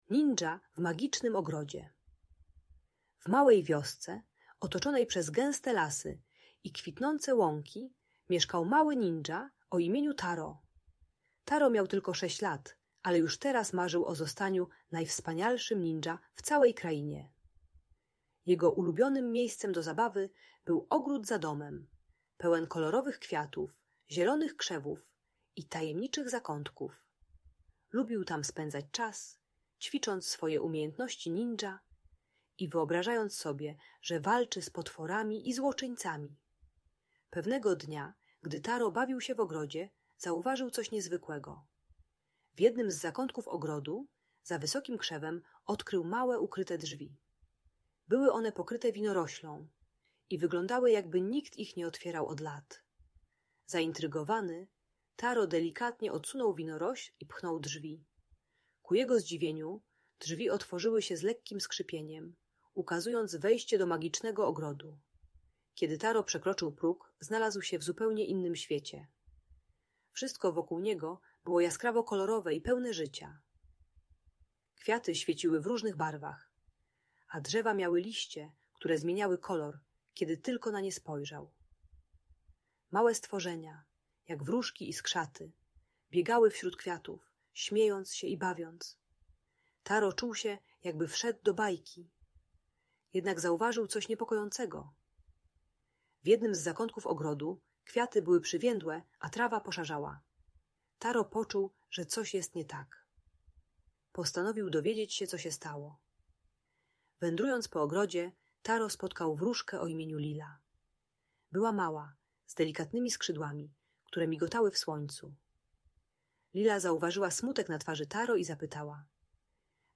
Opowieść o małym ninja - Audiobajka dla dzieci